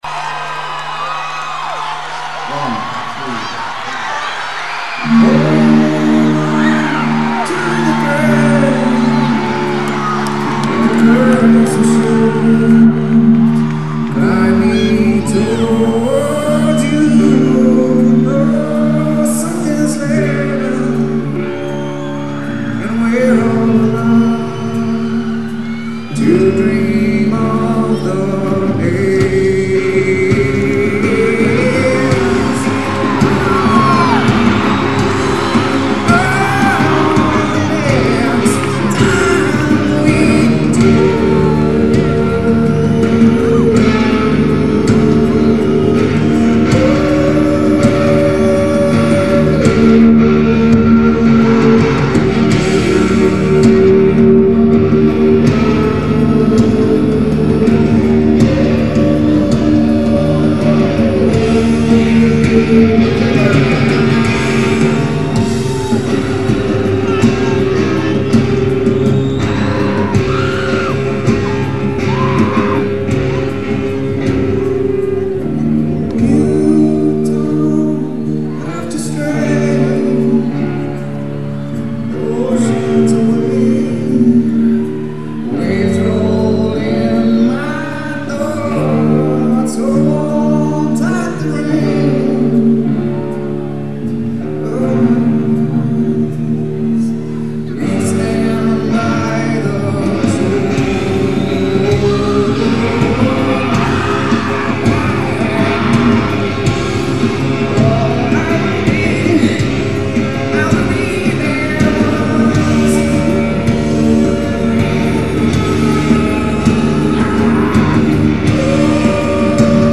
audio: live from 7/8/95